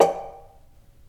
acoustic household percussion sound effect free sound royalty free Memes